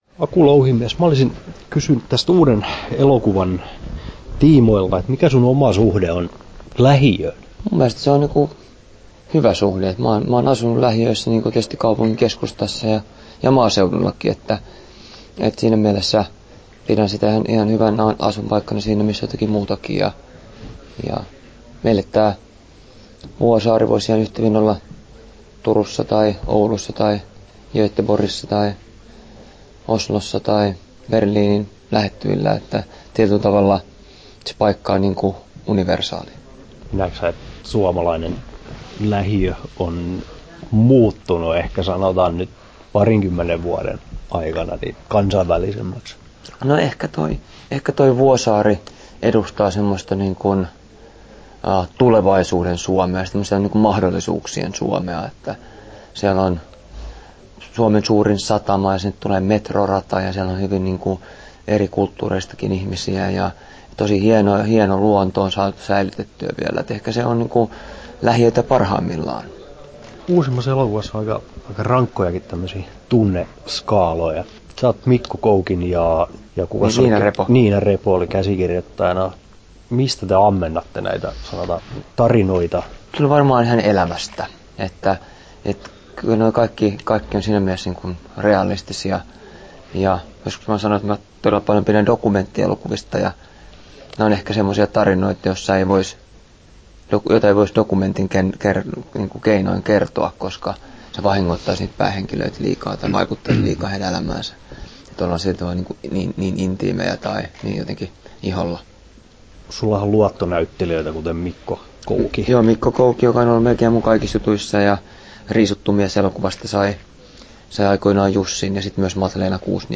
Aku Louhimiehen haastattelu Kesto